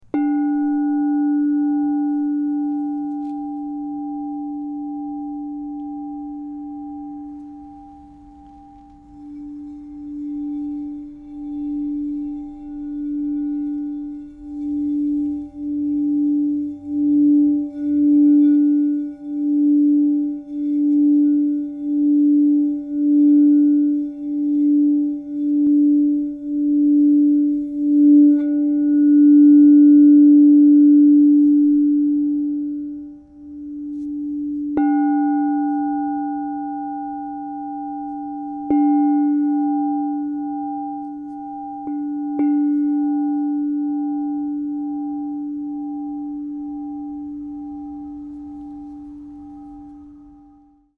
The tall, elegant 7-inch design enhances its rich, resonant tones, making it perfect for meditation, sound therapy, and sacred rituals.
Experience this 7″ Crystal Tones® True Tone alchemy singing bowl made with Lapis Lazuli Tall in the key of D -5.
432Hz (-), 440Hz (TrueTone)